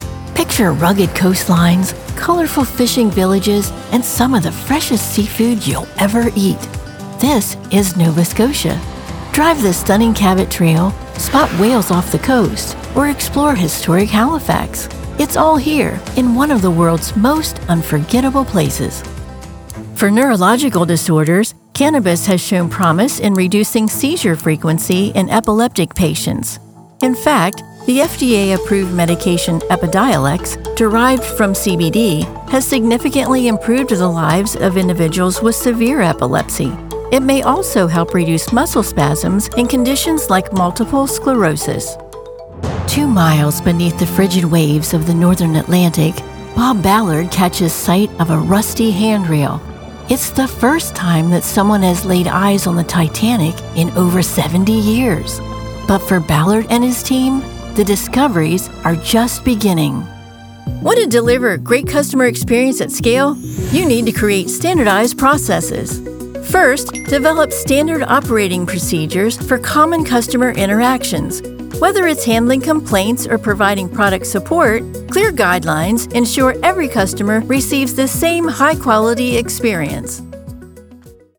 Narration
Southern US
Middle Aged
Senior